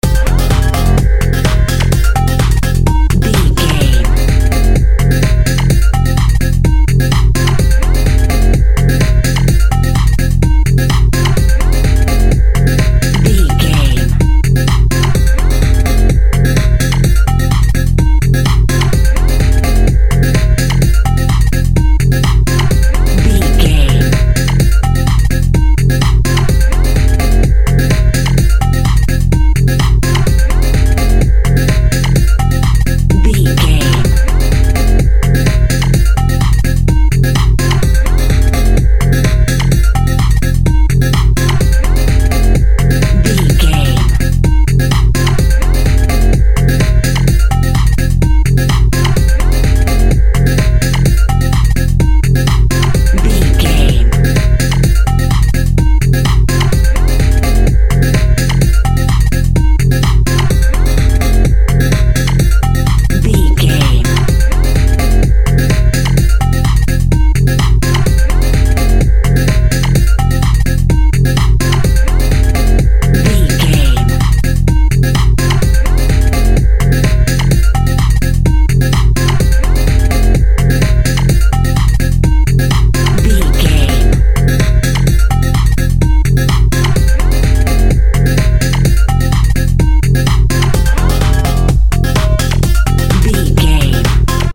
Aeolian/Minor
dark
futuristic
driving
energetic
tension
drum machine
synthesiser
Drum and bass
break beat
electronic
sub bass
synth lead
synth bass